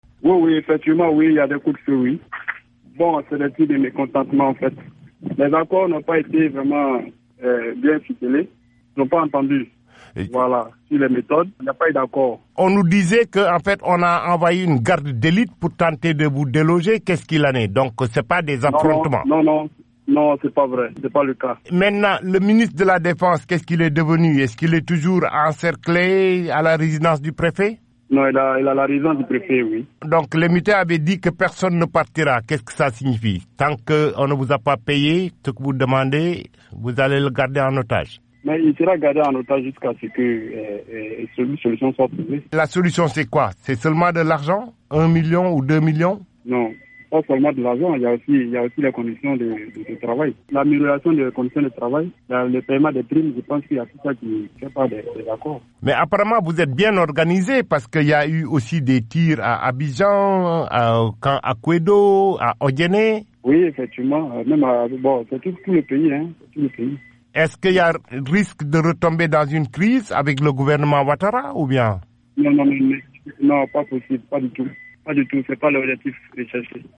Un mutin joint à Bouaké